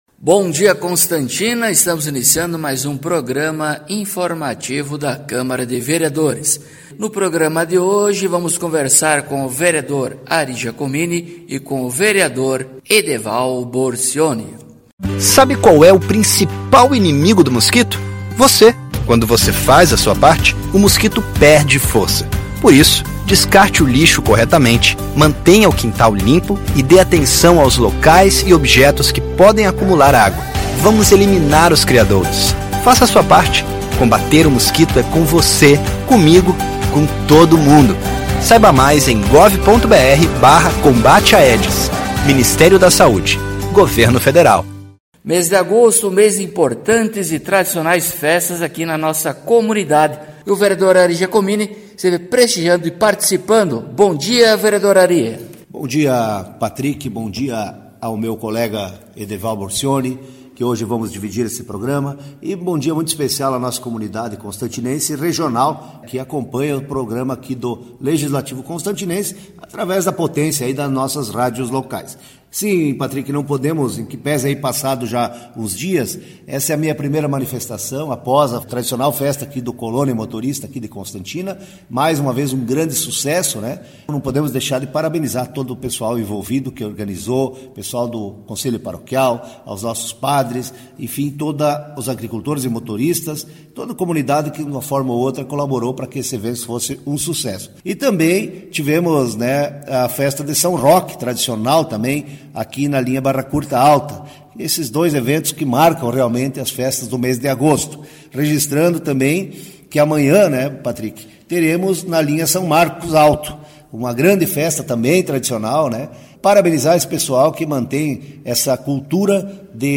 Acompanhe o programa informativo da câmara de vereadores de Constantina com o Vereador Ari Giacomini e o Vereador Edeval Borcioni.